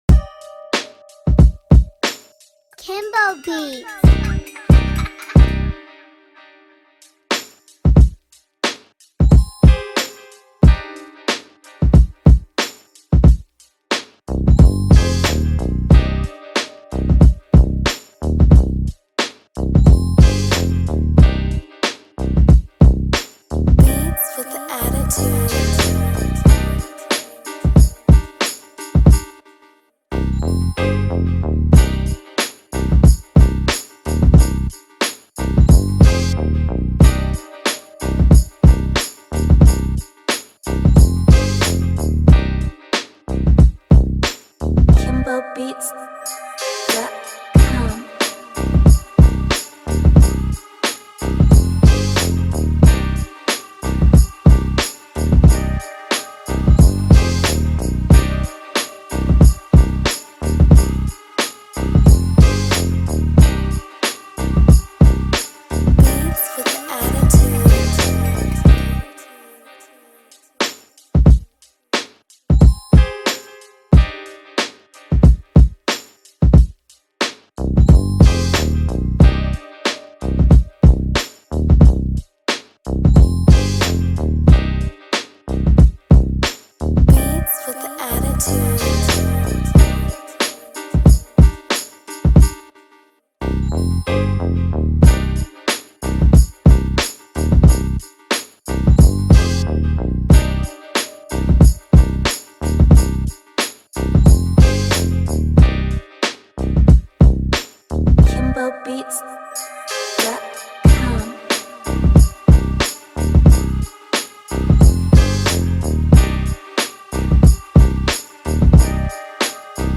Doper Beat ngl.